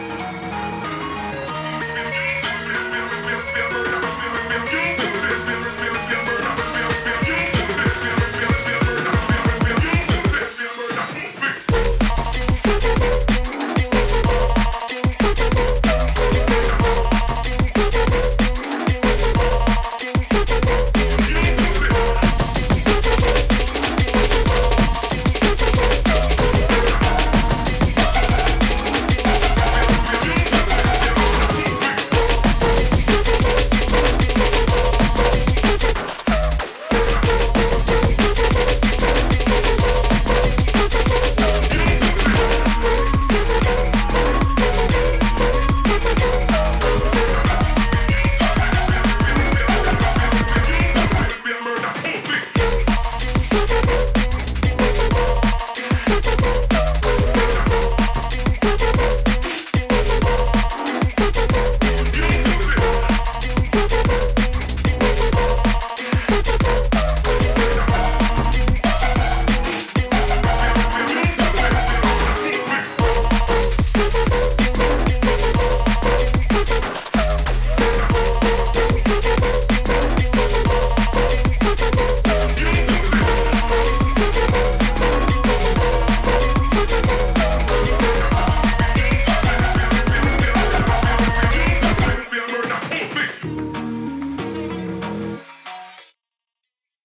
Made a cool remix/smashup for you.